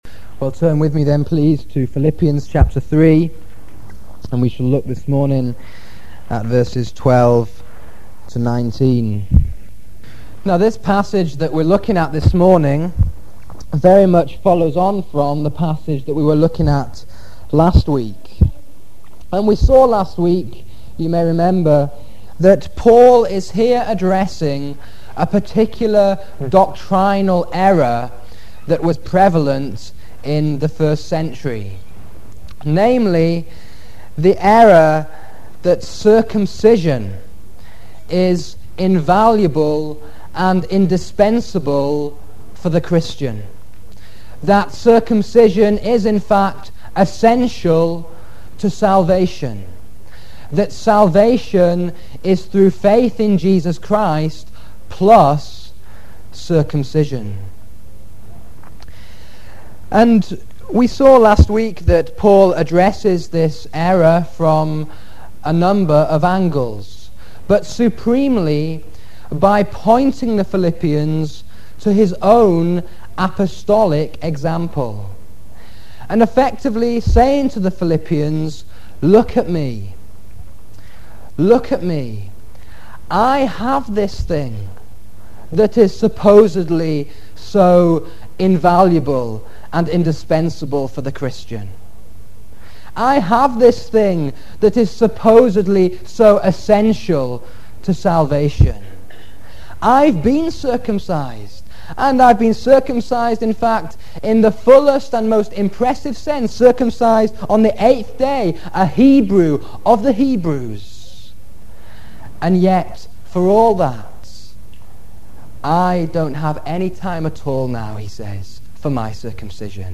Sermons: reverse order of upload